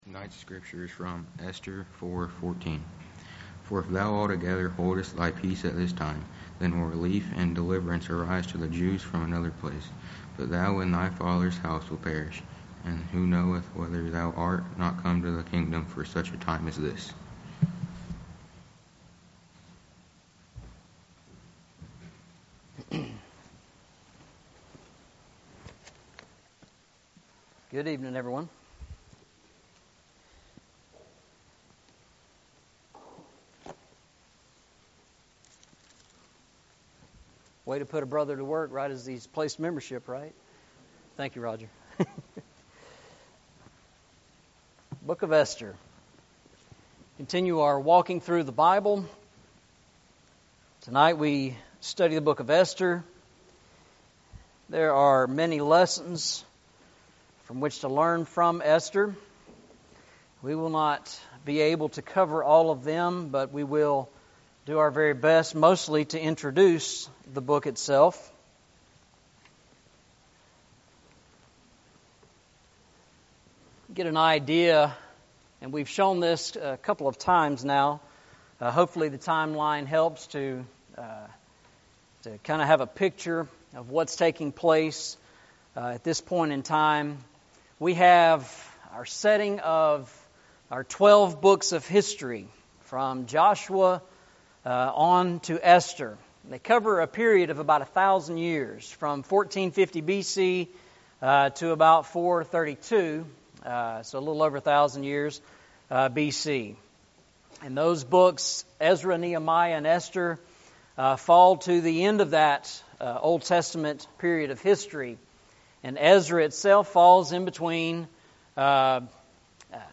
Eastside Sermons Passage: Esther 4:14 Service Type: Sunday Evening « Serving the Lord with Gladness